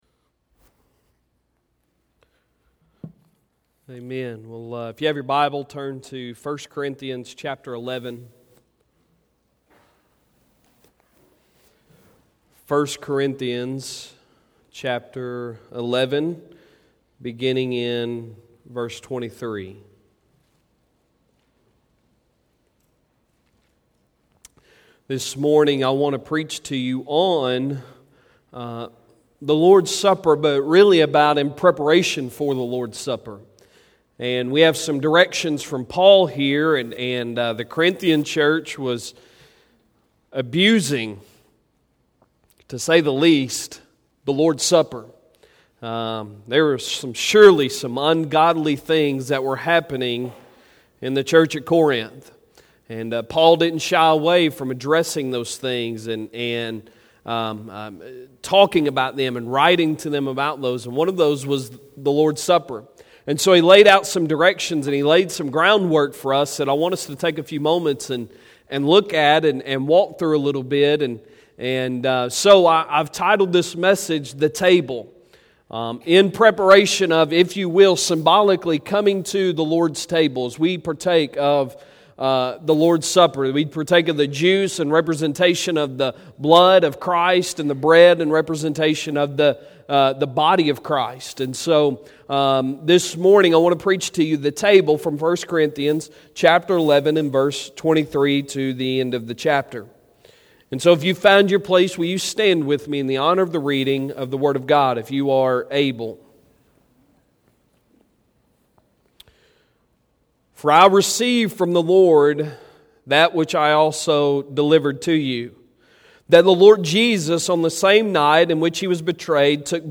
Sunday Sermon October 20, 2019